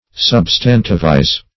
Search Result for " substantivize" : The Collaborative International Dictionary of English v.0.48: Substantivize \Sub"stan*tiv*ize\, v. t. To convert into a substantive; as, to substantivize an adjective.